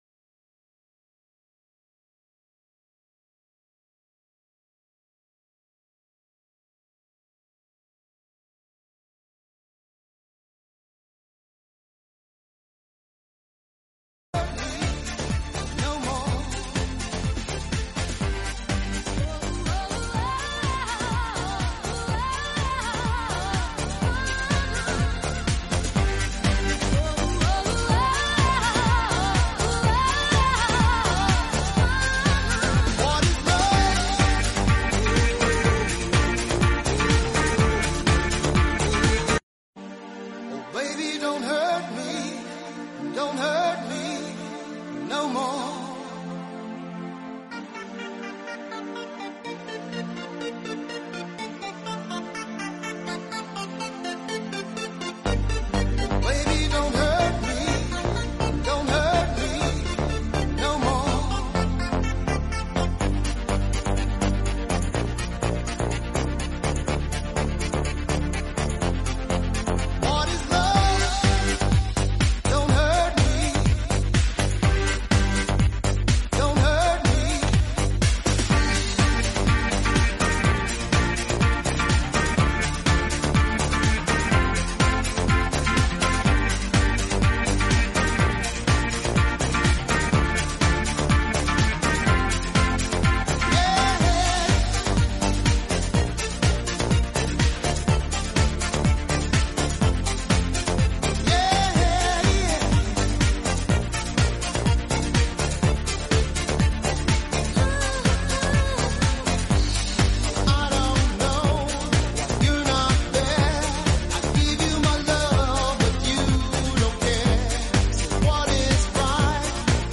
En Directo el homenaje